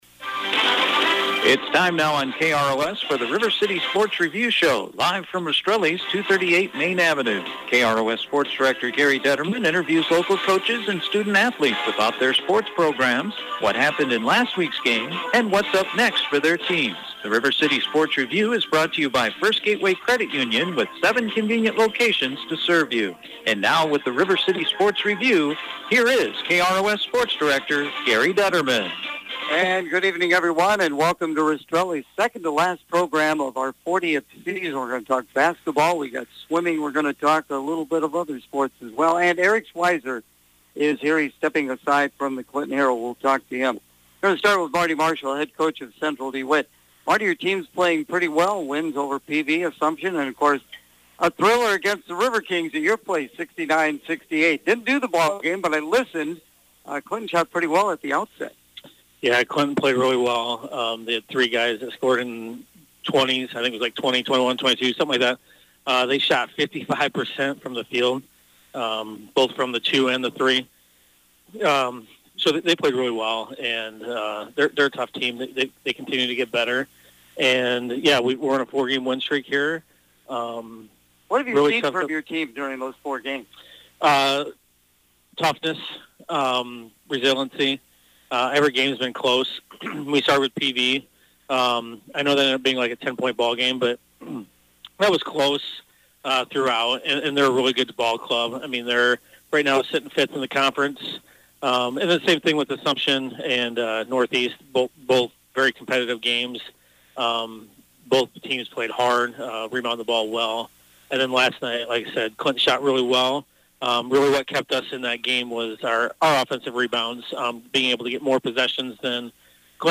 The River City Sports Review Show on Wednesday night from Rastrelli’s Restaurant
with the area coaches to preview this weekends sports action